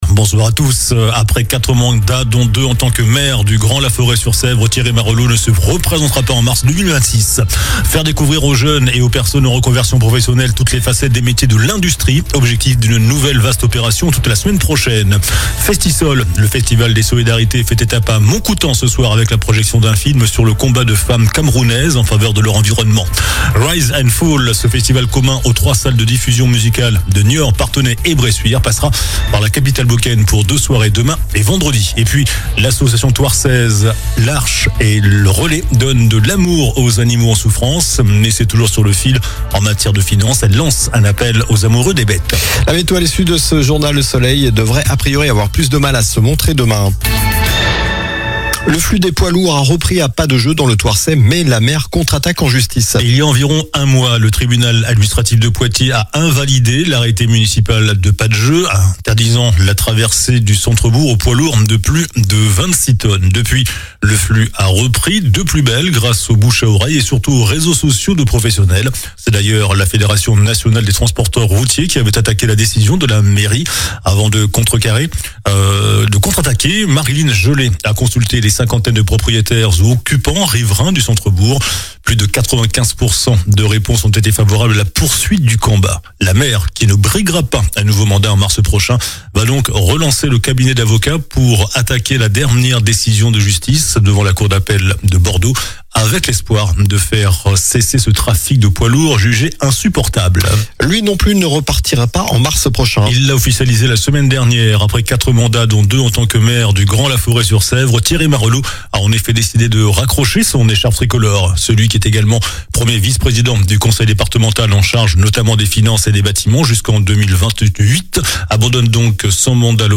JOURNAL DU MERCREDI 12 NOVEMBRE ( SOIR )